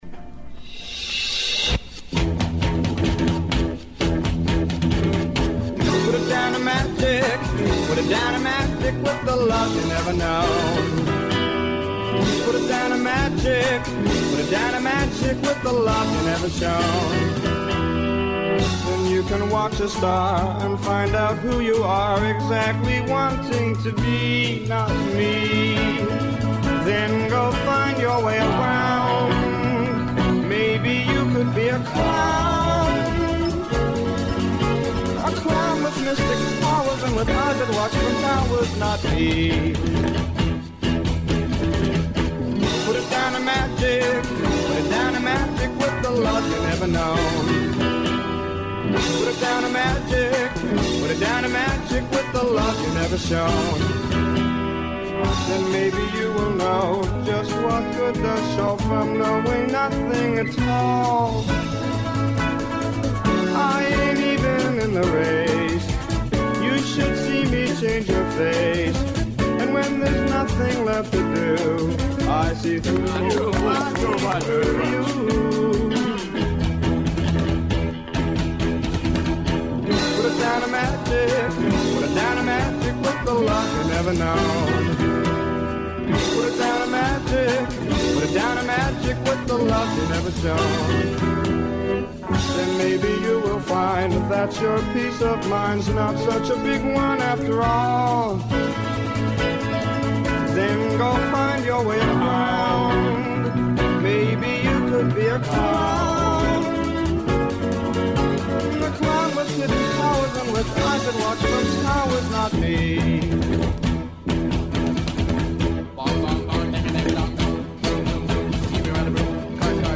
) The show is called Montreal Sound Ark and features music drawn from Archive Montreal’s collections of music, as well as occasional guest DJs and forays into the local music found in CKUT’s music library.